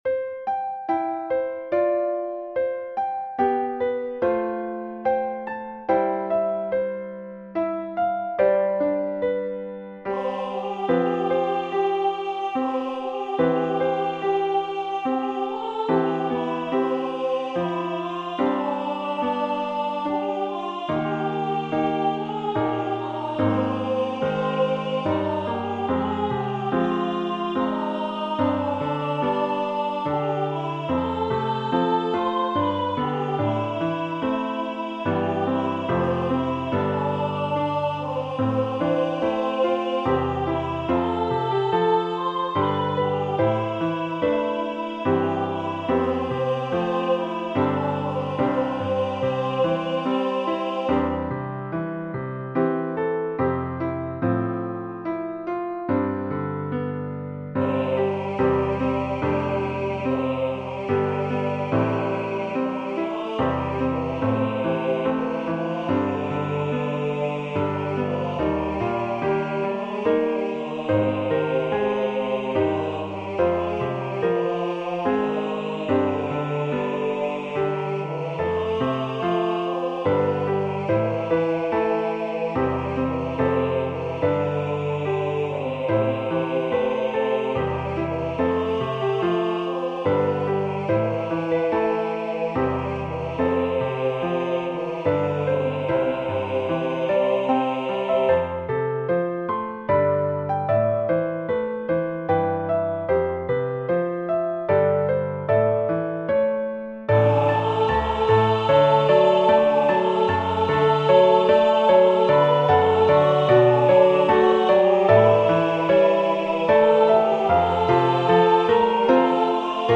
SAB with Piano Accompaniment